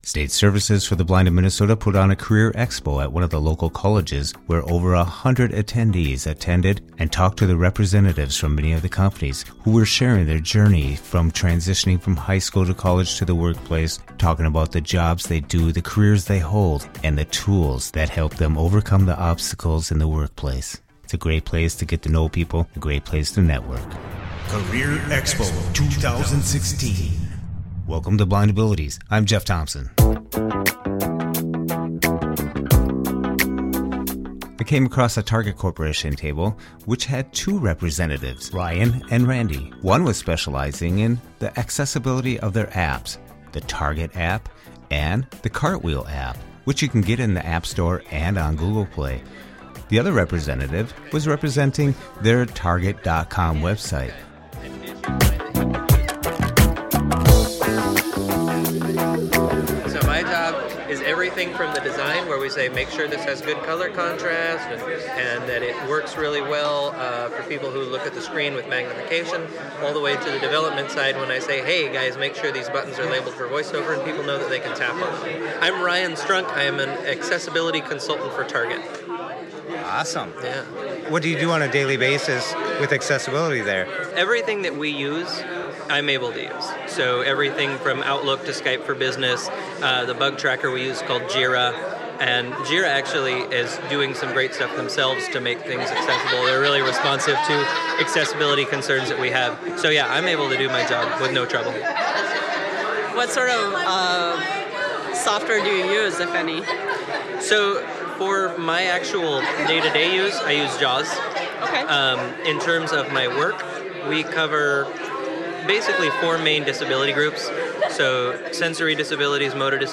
State Services for the Blind of Minn esota put on a Career Expo at a local college in Minneapolis with over 25 representatives from companies who themselves are Blind or visually Impaired. They shared their experiences from transitioning from high school to college and to the work place.